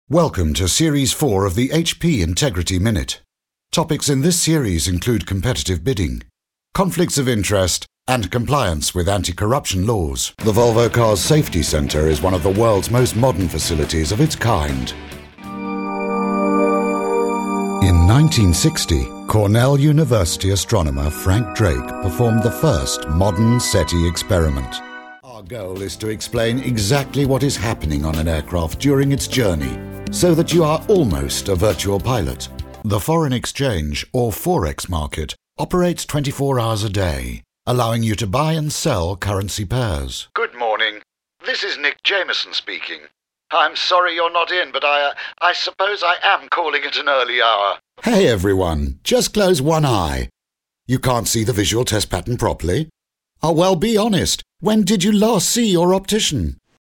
He was given an education that formed his classic English accent - and a voice, deep and warm, that records perfectly and gives that extra edge to any production.
englisch (uk)
Sprechprobe: Industrie (Muttersprache):
A true British voice, warm, friendly & very English.